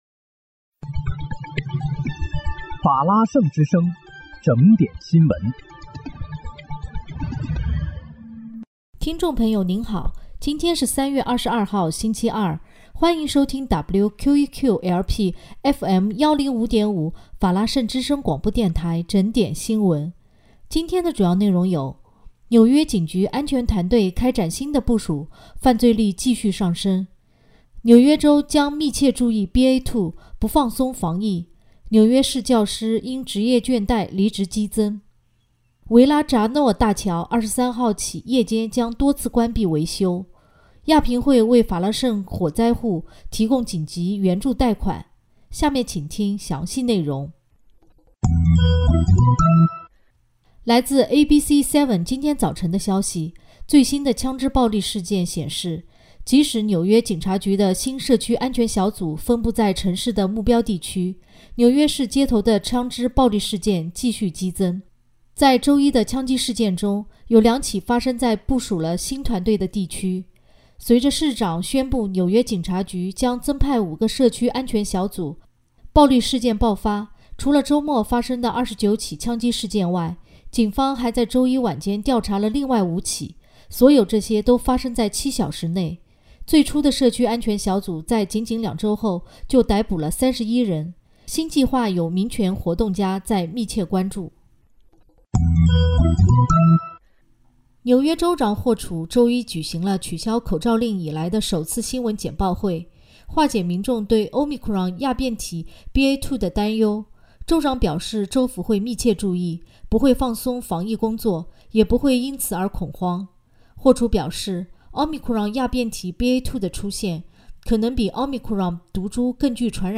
3月22日（星期二）纽约整点新闻
听众朋友您好！今天是3月22号，星期二，欢迎收听WQEQ-LP FM105.5法拉盛之声广播电台整点新闻。